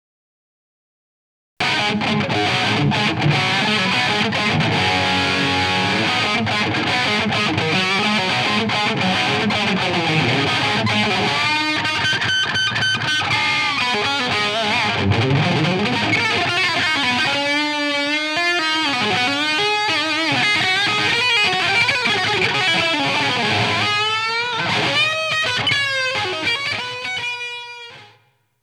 aujourd'hui c'est un seul micro (le '57 +v30) dans le jcm800 boosté pour faire du pseudo brown sound, preampli du fostex model 450 pour le coup , 20 secondes de son :
J'aime beaucoup